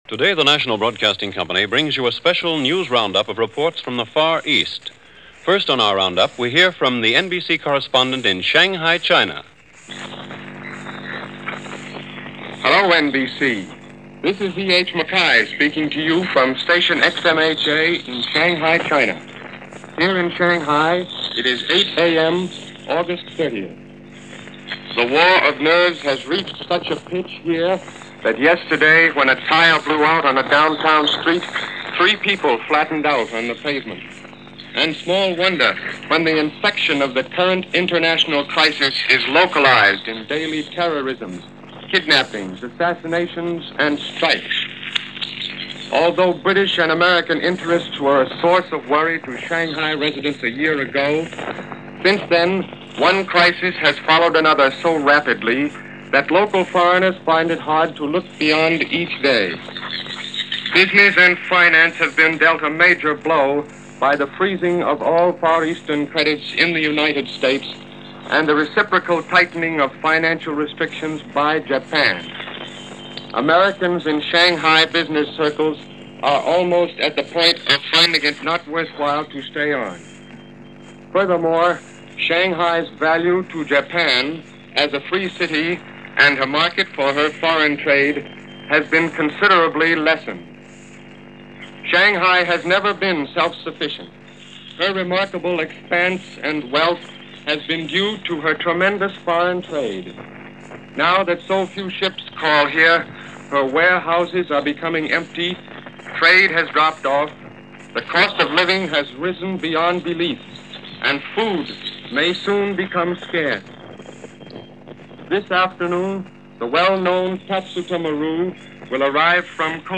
A special Roundup of News from the Pacific front via NBC Blue Network.